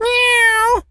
cat_meow_04.wav